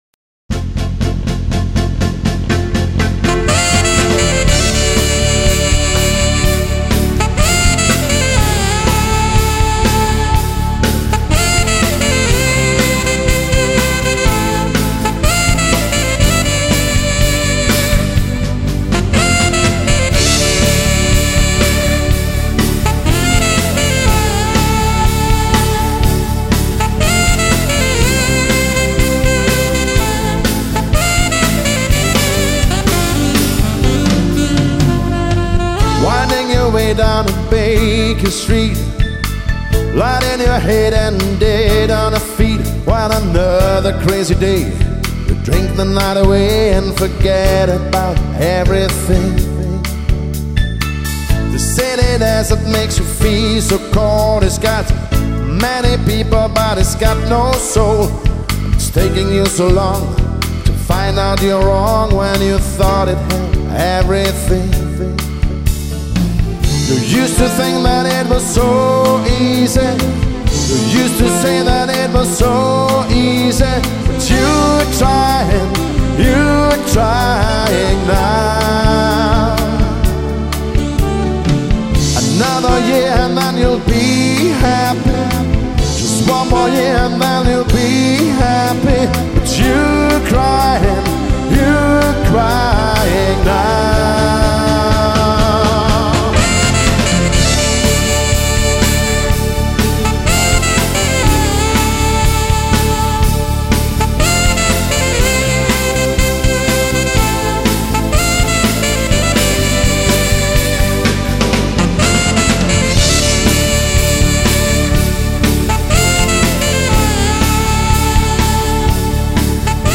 Tanzmusik  -  Soul  -  Funk  -  Pop  -  Rock
Instrumental